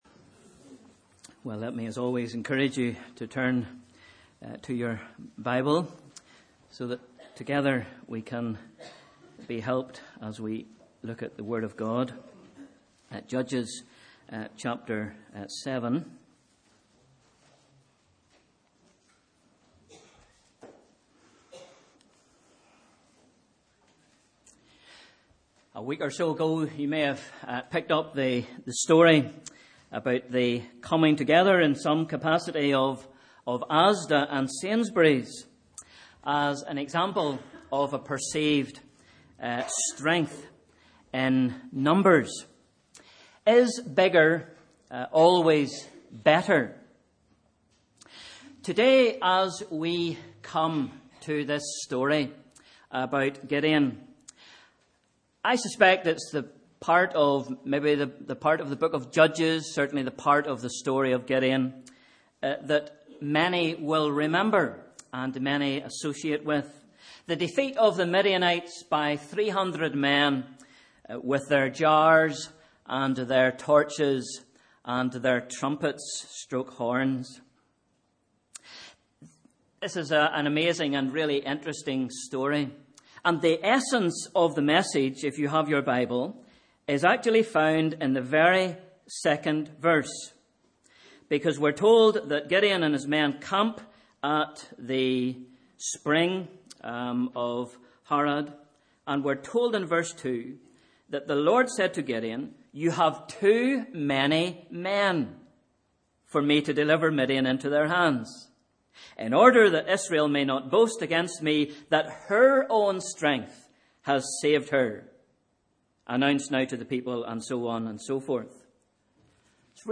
Sunday 13th May 2018 – Morning Service